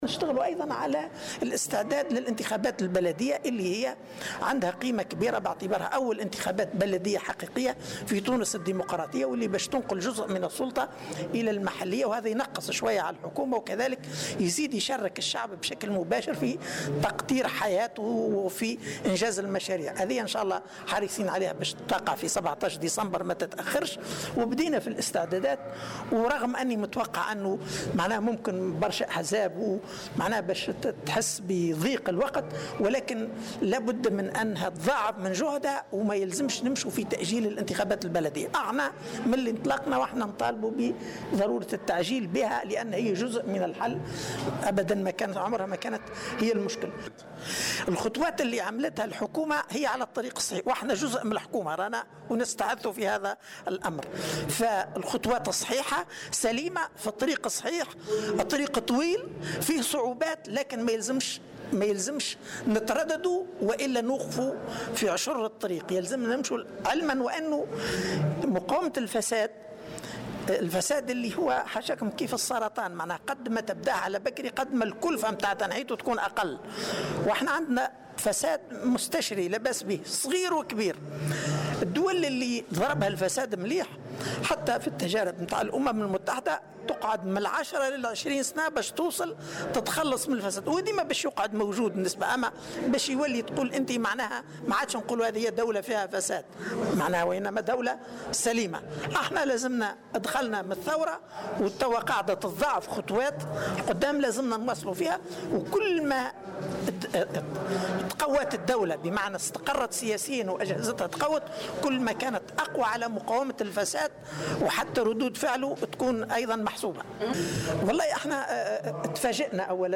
وأضاف على هامش لقاء نظمه أمس المكتب الجهوي للحركة بصفاقس لتسليم المهام بعد التجديد الهيكلي، أن الحركة انطلقت في الاستعداد لهذه الانتخابات، التي تعتبر أول انتخابات بلدية حقيقية في تونس، وفق تعبيره.